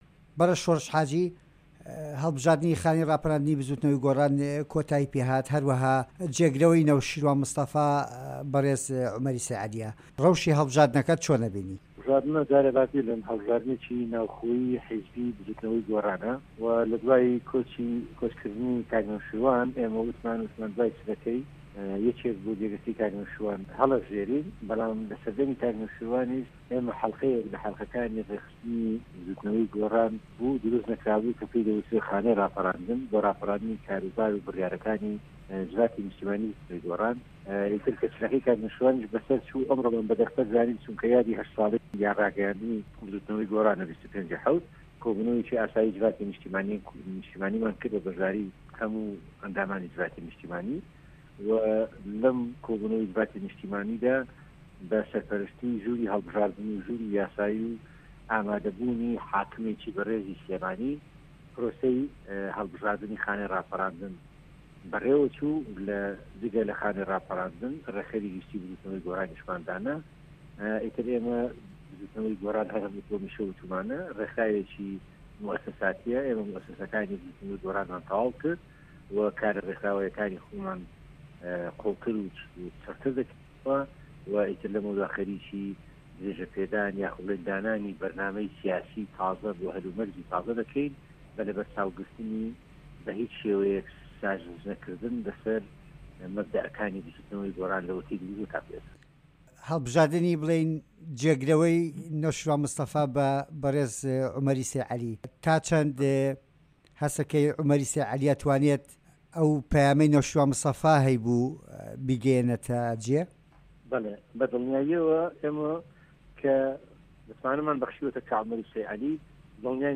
وتووێژ لەگەڵ شۆڕش حاجی